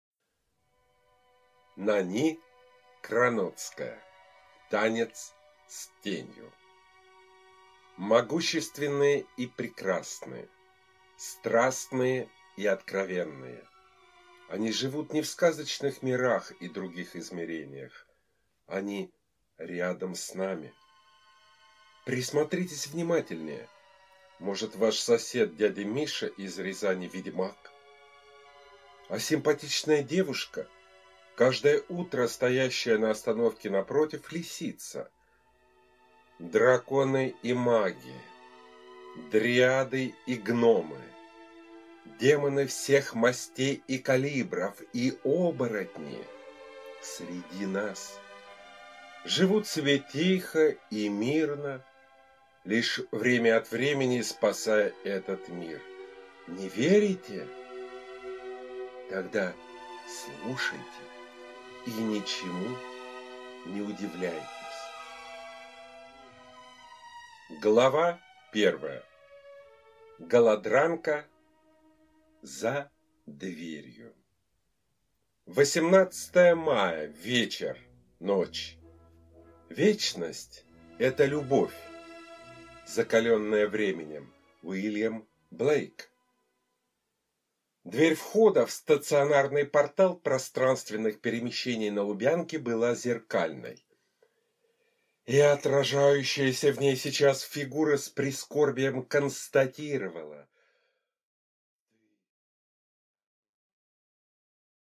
Аудиокнига Танец с тенью | Библиотека аудиокниг